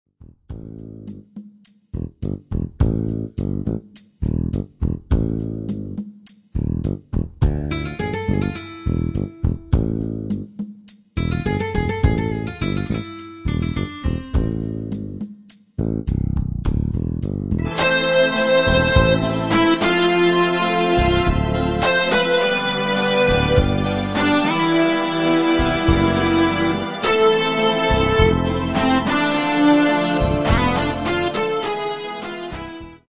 INSTRUMENTAL
Pop